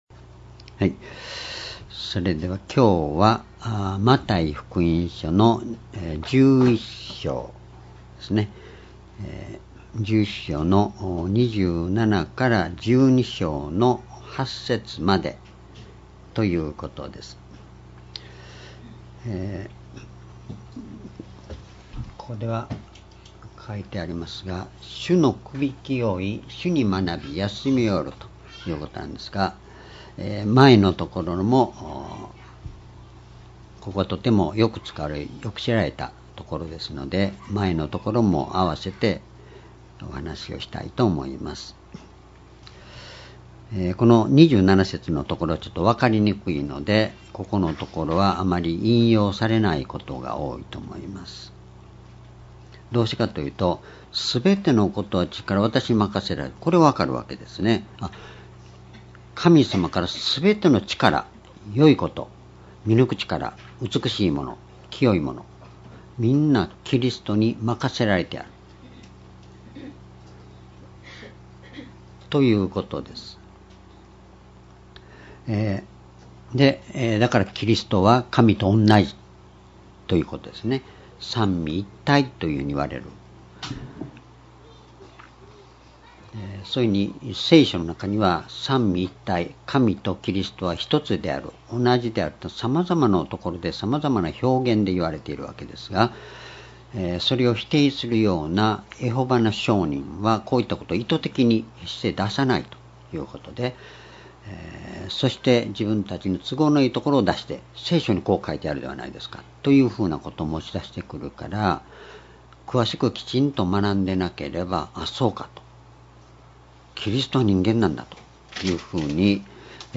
講話 ｢主のくびきを負い､主に学び休みを得る｣ マタイ福音書11章27節-12章8節 2017年9月10日 主日